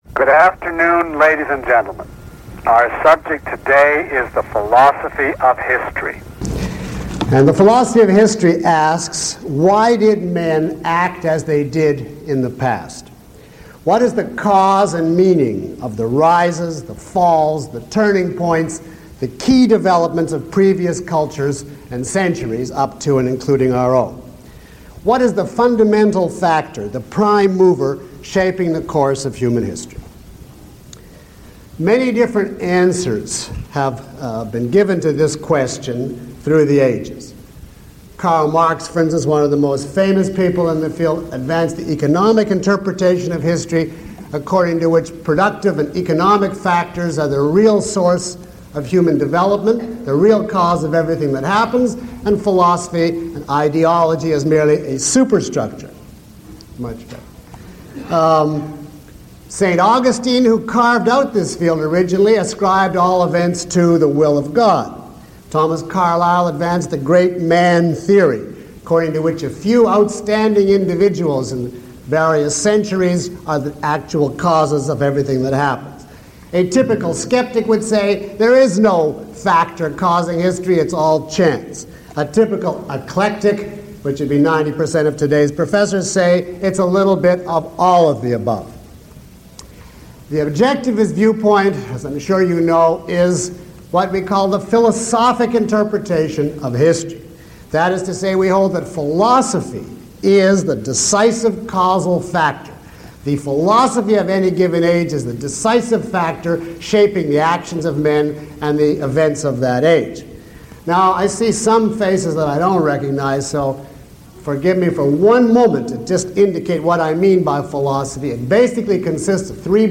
Lecture (MP3) Questions about this audio?
Below is a list of questions from the audience taken from this lecture, along with (approximate) time stamps.